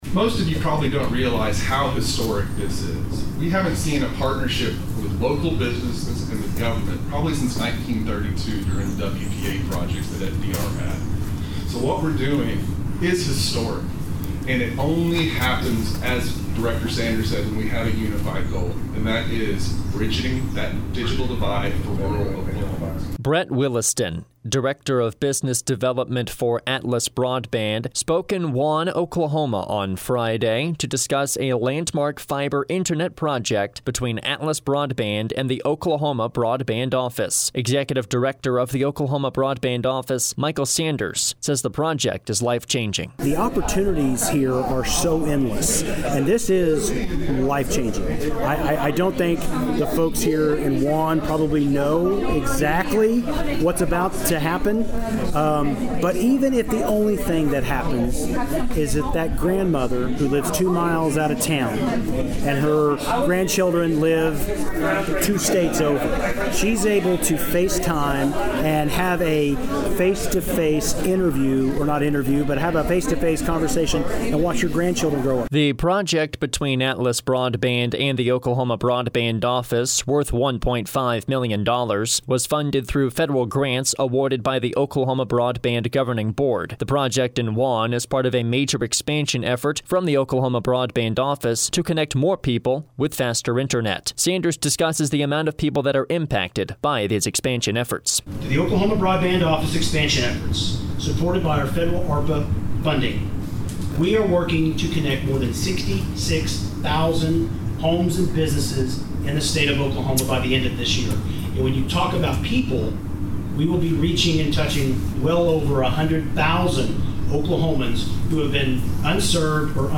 CLICK HERE FOR THE FULL VOICER.
Executive Director of the Oklahoma Broadband Office Michael Sanders says the project is life-changing.
Mayor of Wann Mike Stainbrook discusses the potential economic growth the project brings.